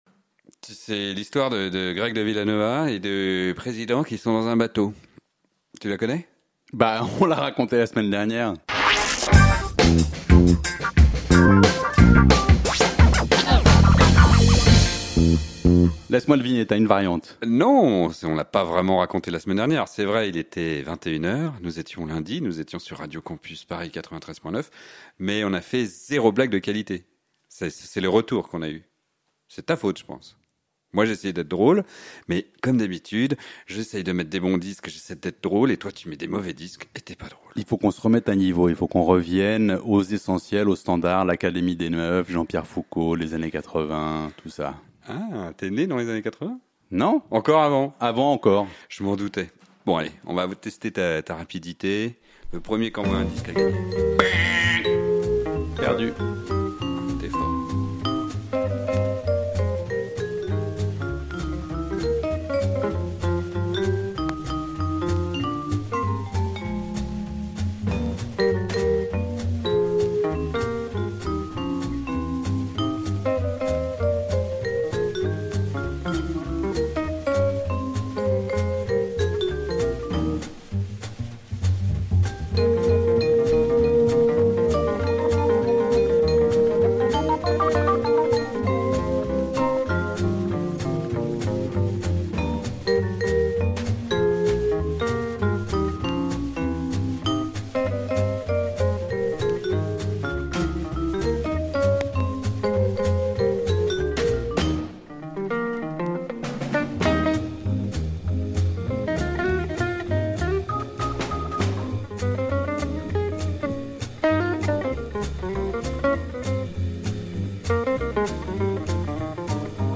Chaque disque en appelle un autre.